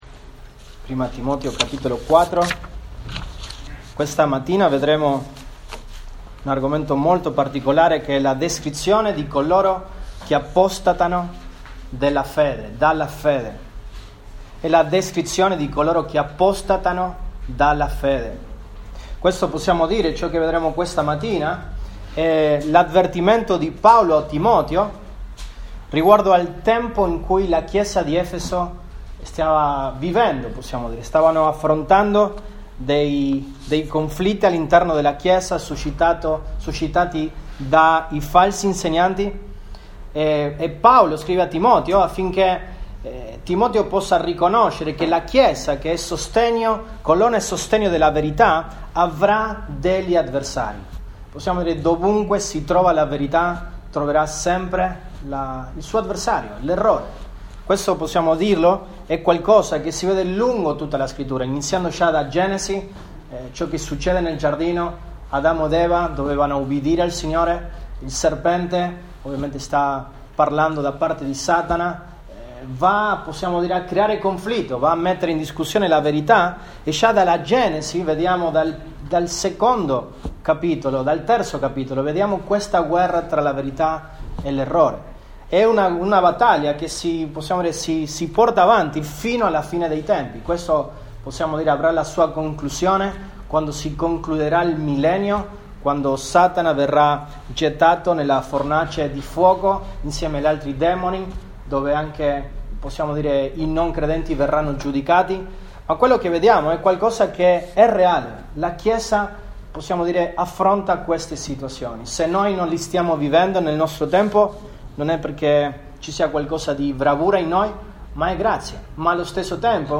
Giu 02, 2021 La descrizione di coloro che apostatano dalla fede MP3 Note Sermoni in questa serie La descrizione di coloro che apostatano dalla fede.
Chiesa Biblica Messina